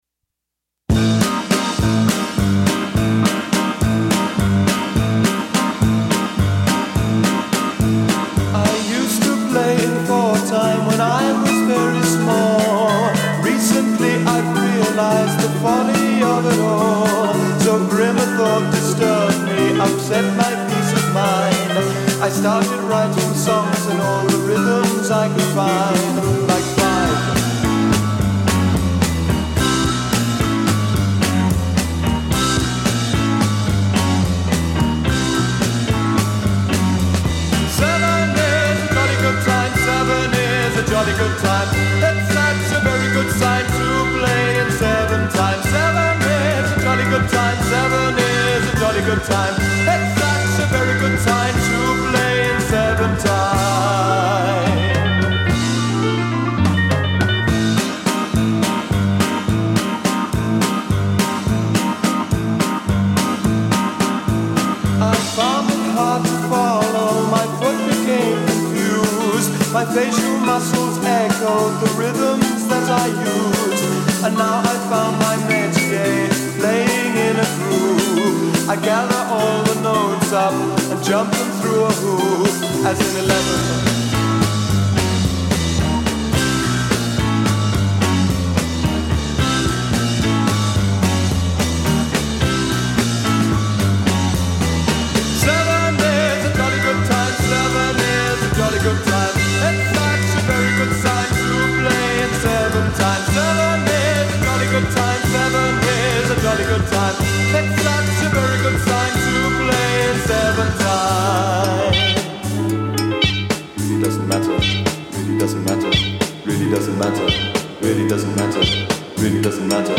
Set the mind in the mood of gladness in odd time signatures.